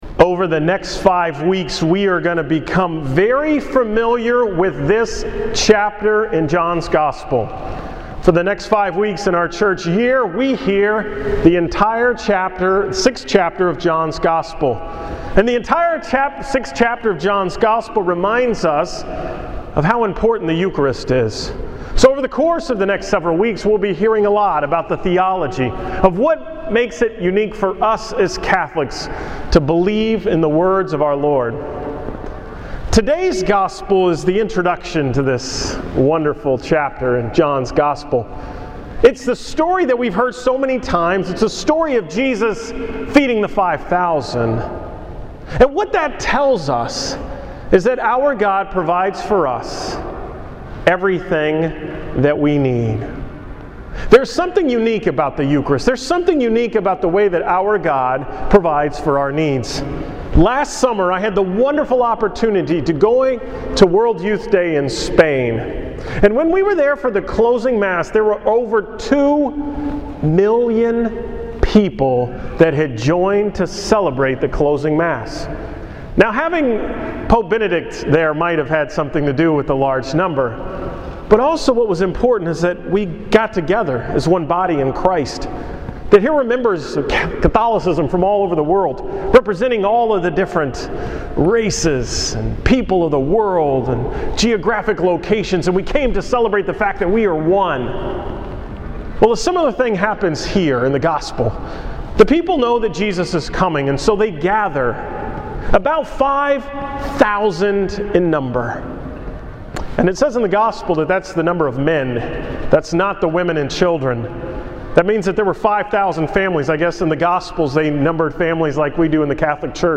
Homily from Sunday, July 29th